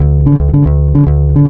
JAZZBASS  -L.wav